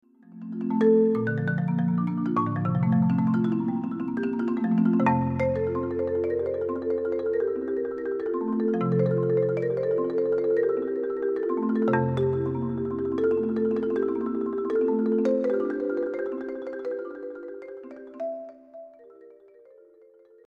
For solo marimba